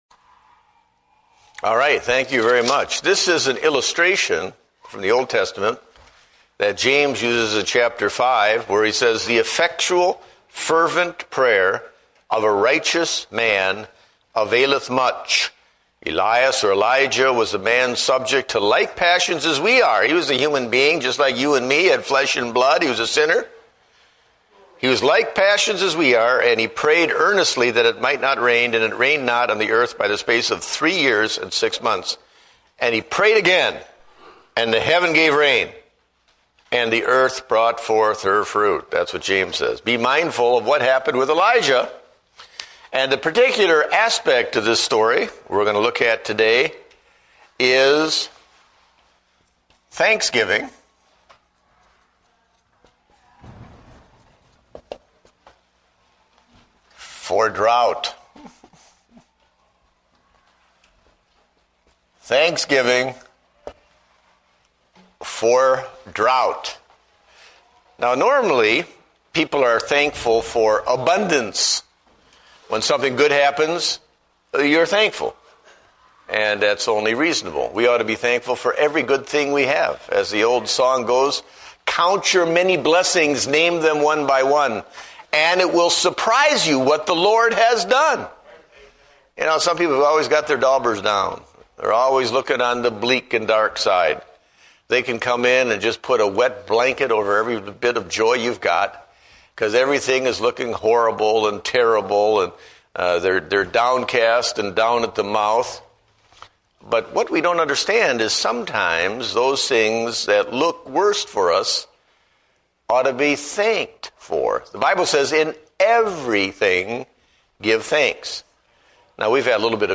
Date: November 23, 2008 (Adult Sunday School)